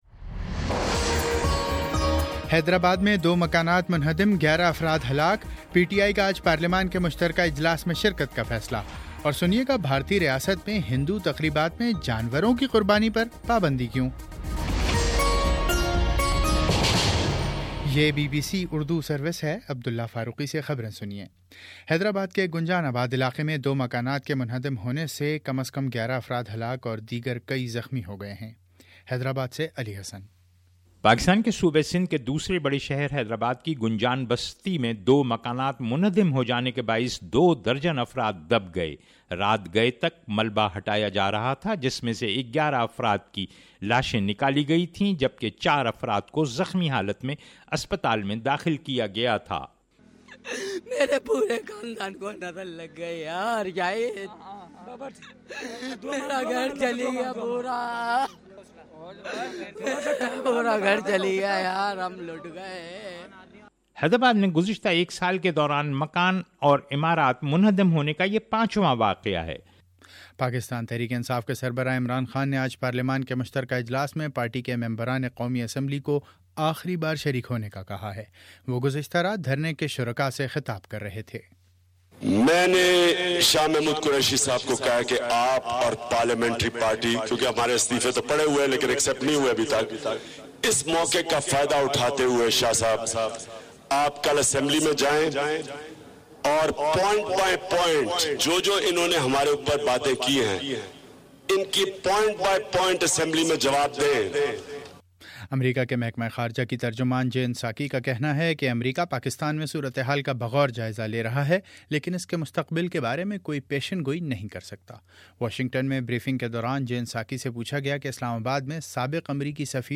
تین ستمبر : صبح نو بجے کا نیوز بُلیٹن
دس منٹ کا نیوز بُلیٹن روزانہ پاکستانی وقت کے مطابق صبح 9 بجے، شام 6 بجے اور پھر 7 بجے۔